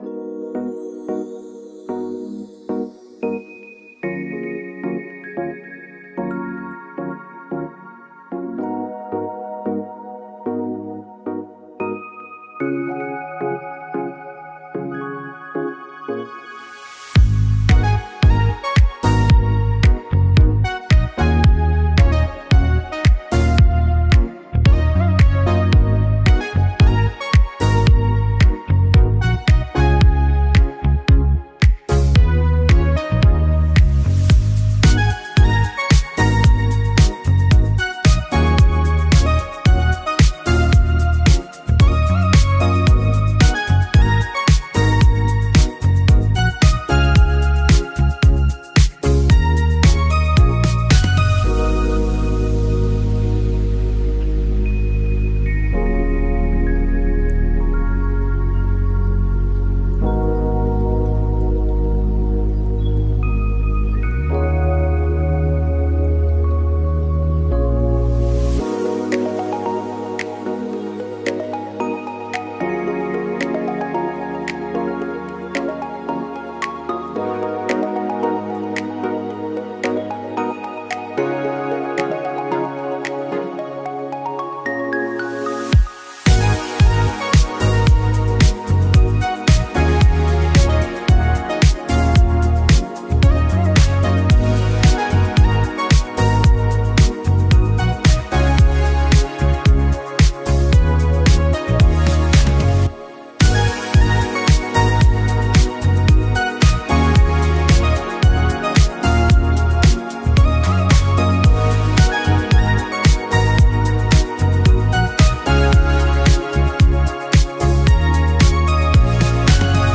未来的で心地よいシンセポップ、きらめく虹色のサウンド、デジタルチャイム、温かみのあるベース、112bpm
未来的で心地よいシンセポップ。